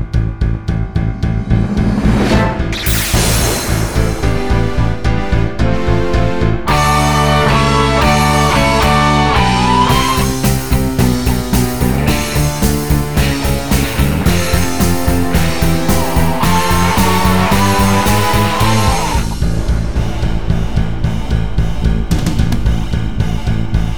no Backing Vocals or movie clips Rock 2:45 Buy £1.50